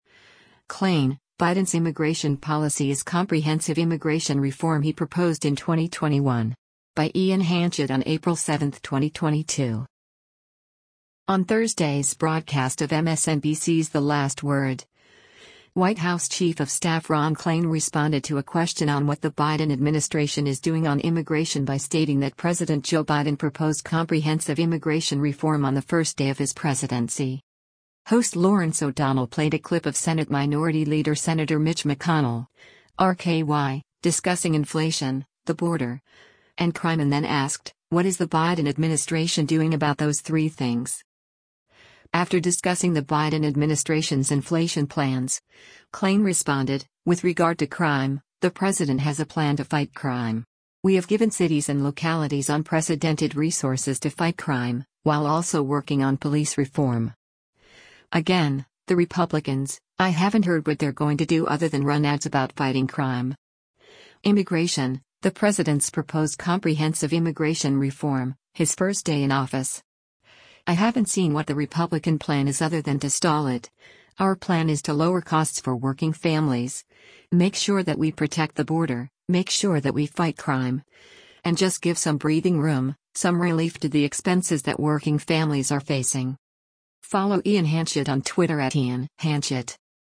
On Thursday’s broadcast of MSNBC’s “The Last Word,” White House Chief of Staff Ron Klain responded to a question on what the Biden administration is doing on immigration by stating that President Joe Biden “proposed comprehensive immigration reform” on the first day of his presidency.
Host Lawrence O’Donnell played a clip of Senate Minority Leader Sen. Mitch McConnell (R-KY) discussing inflation, the border, and crime and then asked, “What is the Biden administration doing about those three things?”